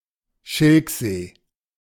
Schilksee (German pronunciation: [ˈʃɪlkˌzeː]
De-Schilksee.ogg.mp3